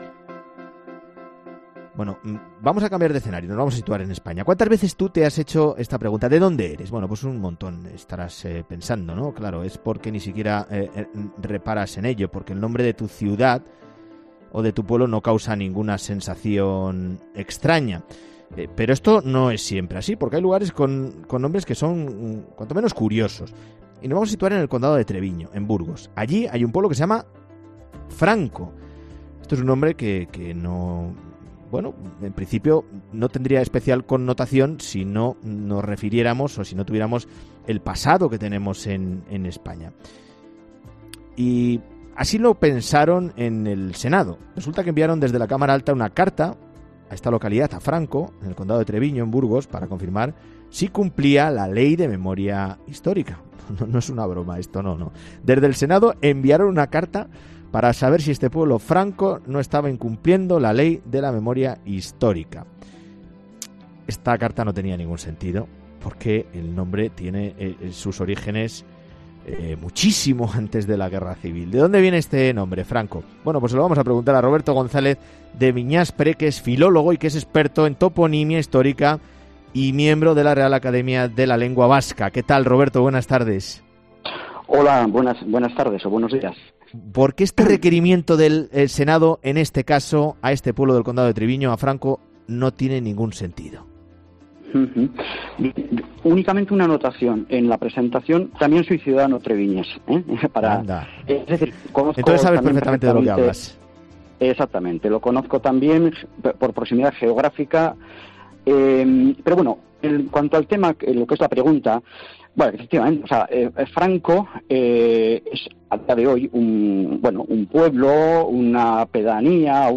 Es filólogo, experto en toponimia histórica y miembro de la Real Academia de la Lengua Vasca.